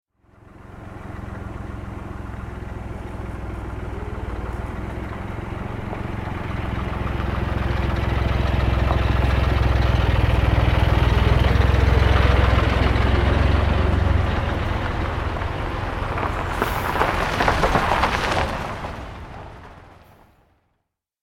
دانلود آهنگ کامیون 7 از افکت صوتی حمل و نقل
دانلود صدای کامیون 7 از ساعد نیوز با لینک مستقیم و کیفیت بالا